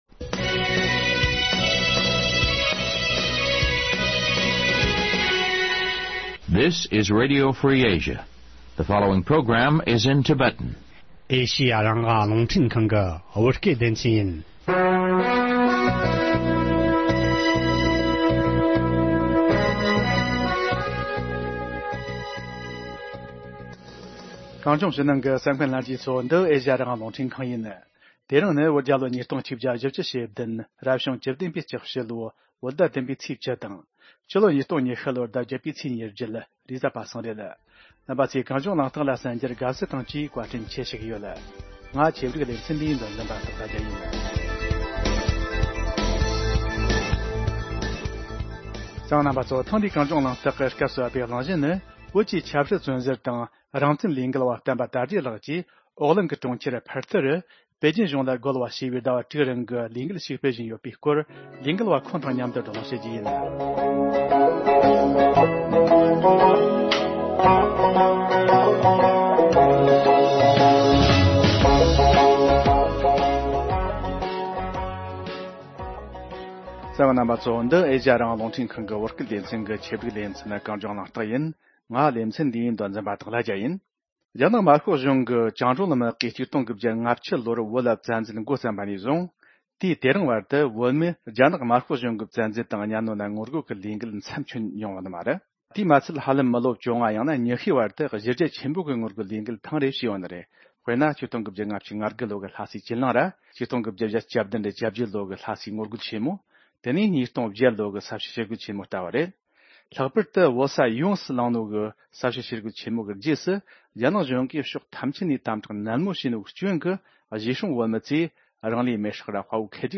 ལས་འགུལ་བ་ཁོང་དང་མཉམ་བགྲོ་གླེང་ཞུས་པ་གསན་རོགས་གནང་།